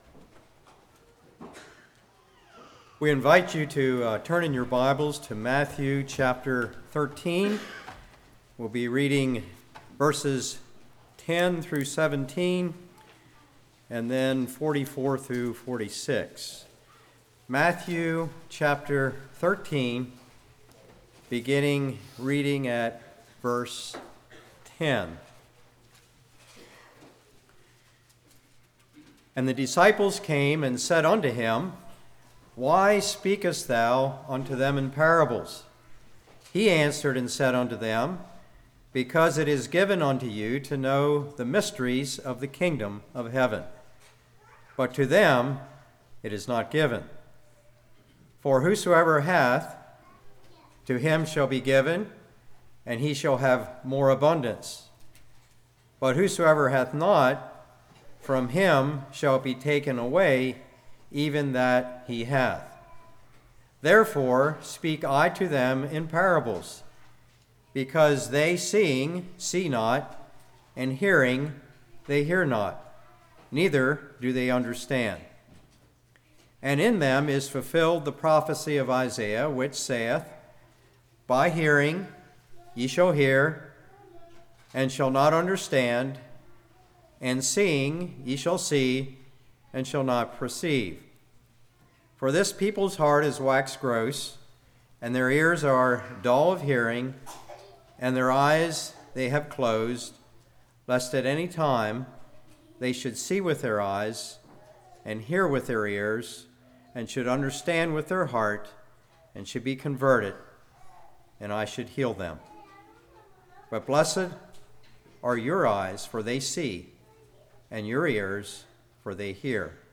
44-46 Service Type: Morning Shows Jesus as King Jesus Knows Some Stumble on a Treasure Some Search and Find « Who Touched Me?